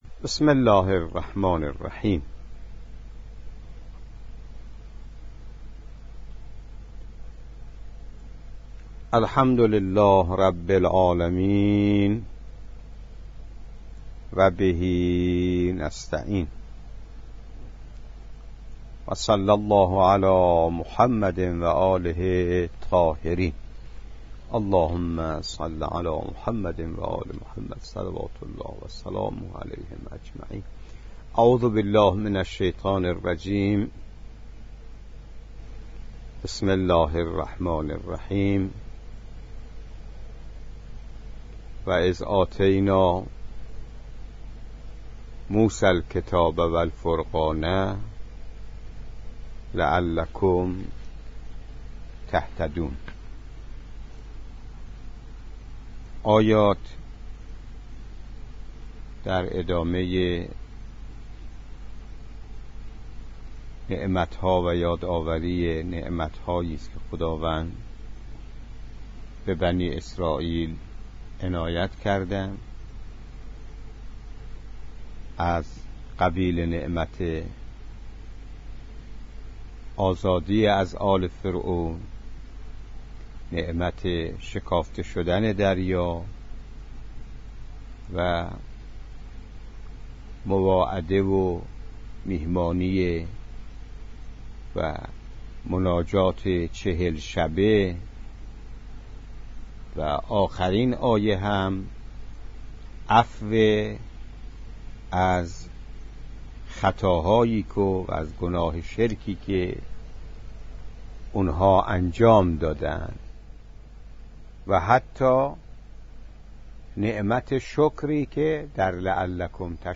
تفسیر آیه 53 سوره بقره